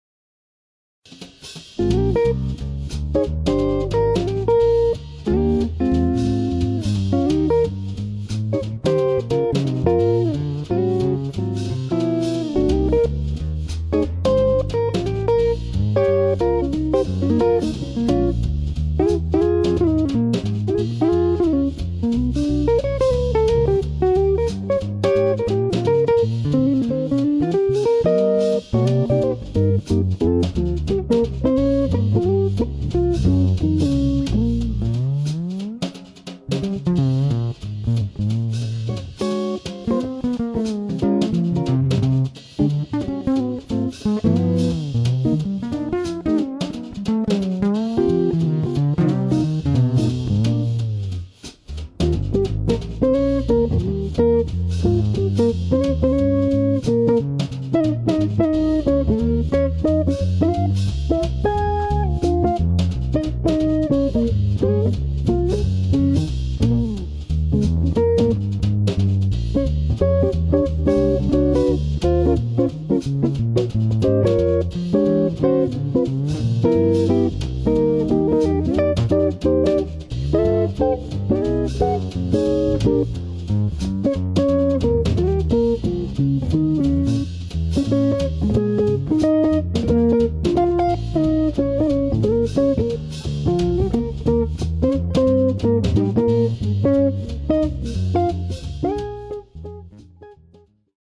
Here are a few samples of my guitar playing: (I plan to add more pictures along with some clips from old bands when I can get time to go through my stored memorabilia)
jazz standard - Bb Blues